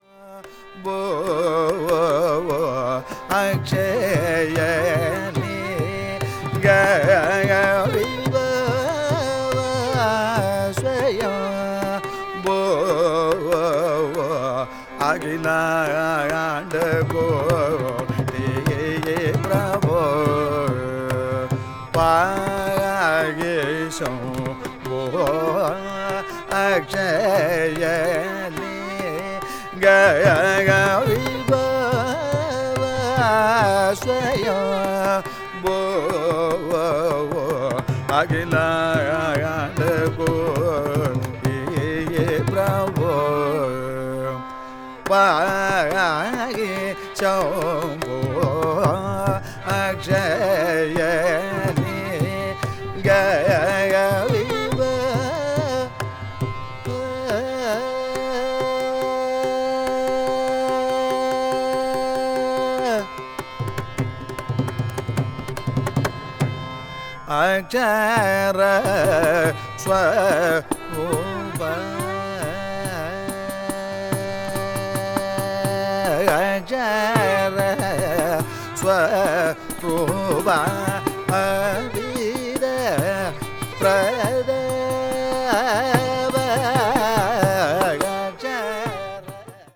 media : EX-/EX-(わずかにチリノイズが入る箇所あり,再生音に影響ない薄い擦れ/薄いスリキズあり)